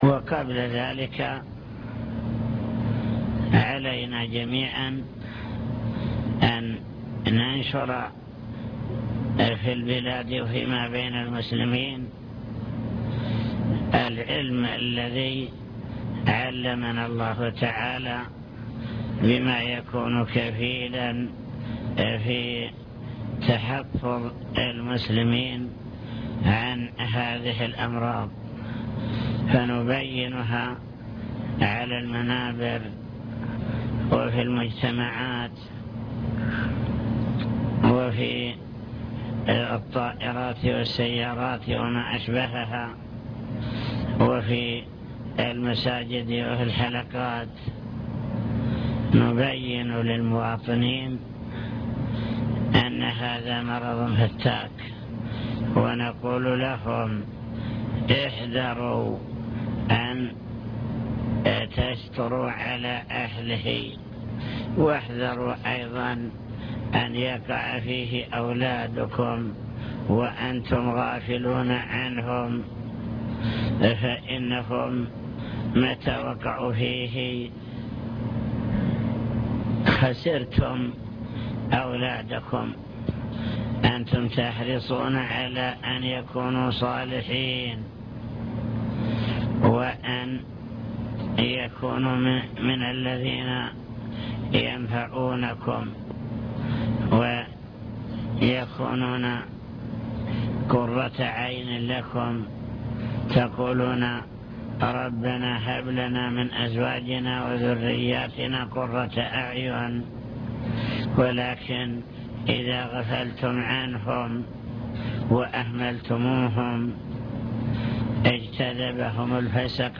المكتبة الصوتية  تسجيلات - لقاءات  كلمة للعاملين في مكافحة المخدرات أضرار المخدرات